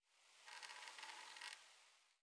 wood strain_05.wav